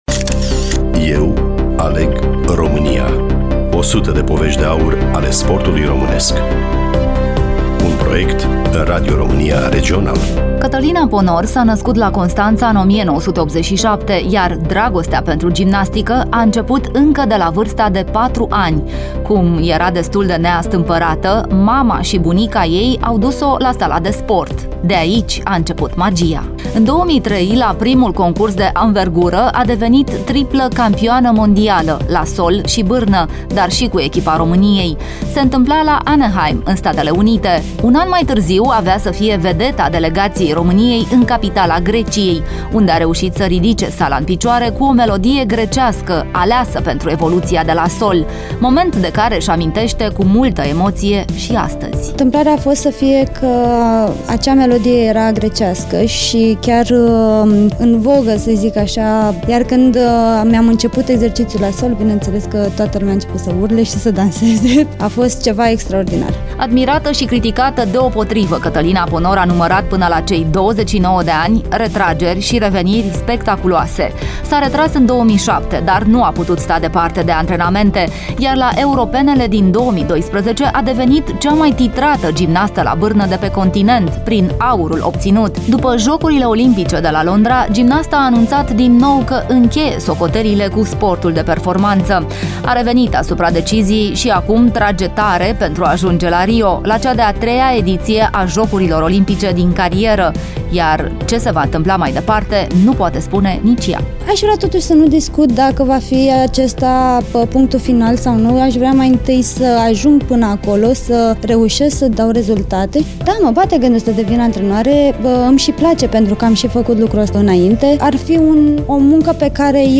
Studioul Radio România Constanţa
Catalina-Ponor-Radio.mp3